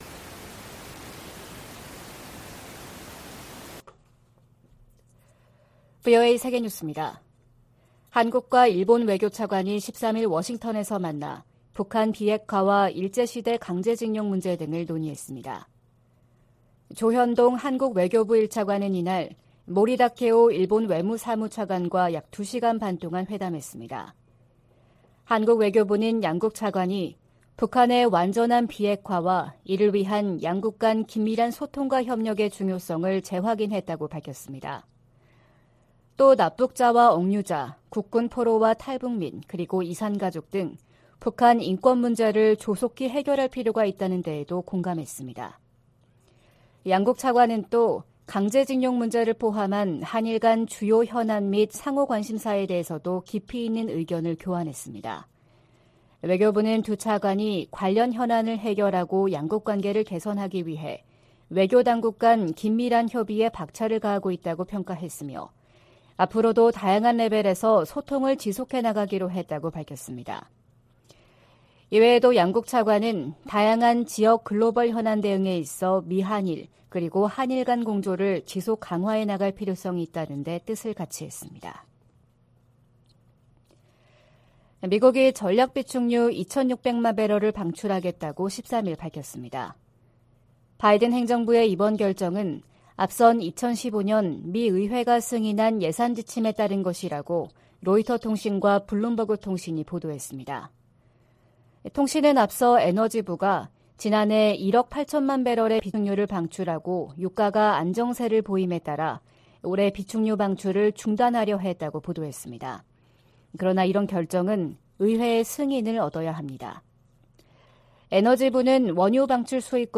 VOA 한국어 '출발 뉴스 쇼', 2023년 2월 15일 방송입니다. 미국과 한국, 일본의 외교 차관들이 워싱턴에서 회의를 열고 북한의 핵과 미사일 위협에 대응해 삼각 공조를 강화하기로 했습니다. 미국 정부는 중국 등에 유엔 안보리 대북 결의의 문구와 정신을 따라야 한다고 촉구했습니다. 백악관은 중국의 정찰풍선이 전 세계 수십 개 국가를 통과했다는 사실을 거듭 확인했습니다.